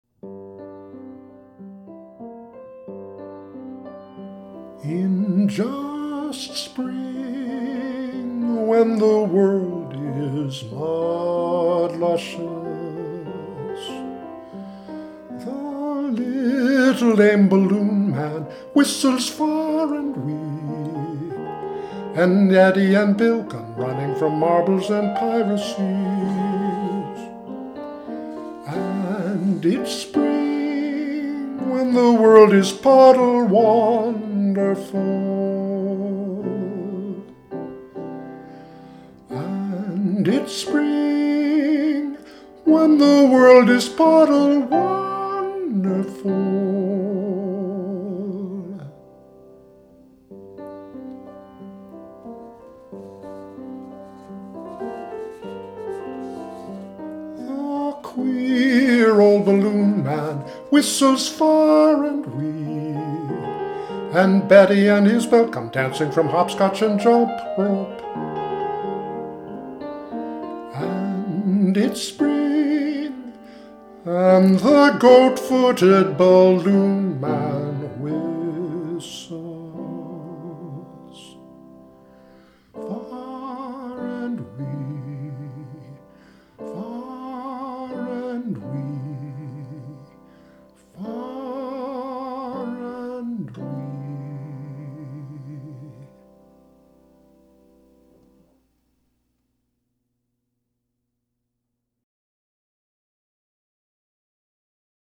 Song setting
The music score for high voice and piano and the mp3 may be downloaded without charge.